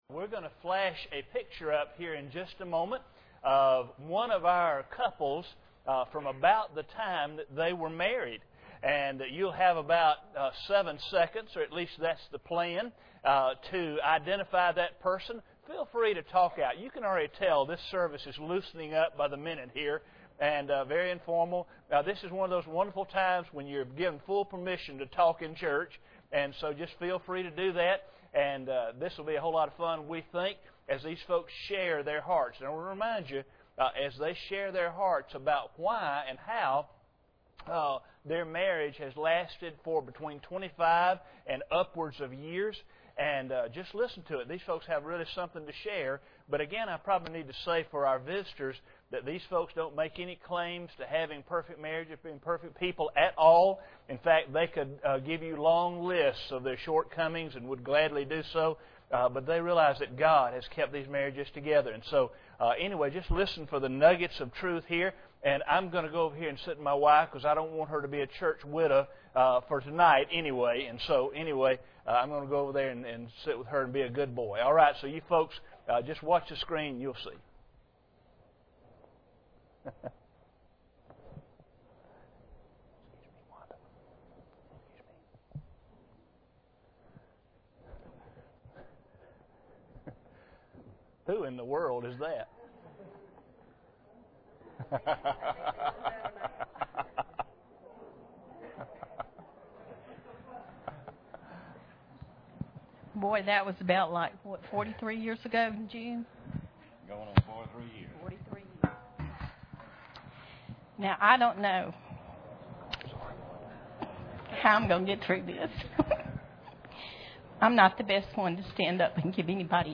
Preacher: CCBC Members | Series: General
April 10, 2011 Steadfast Marriages – Part 2 Speaker: CCBC Members Series: General Service Type: Sunday Evening Preacher: CCBC Members | Series: General This is a continuation of the celebration of marriage.